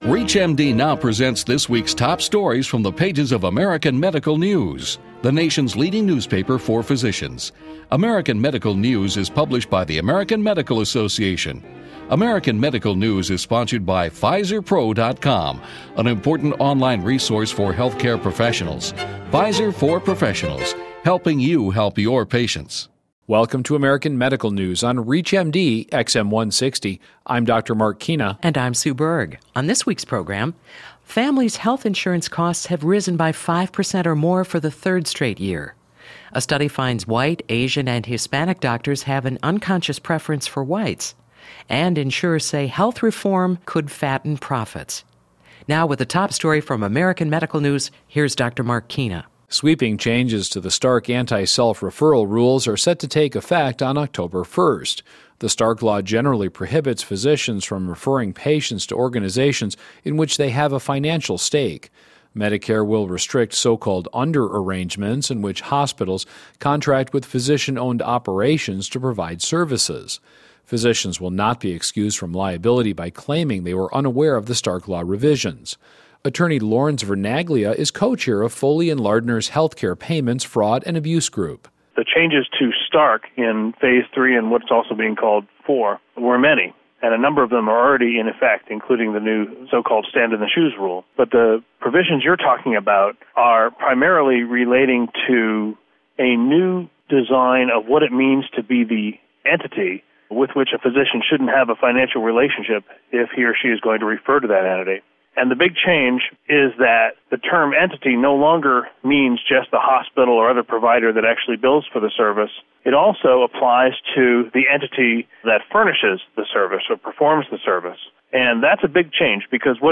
ReachMD_9-28-09_Radio_interview.mp3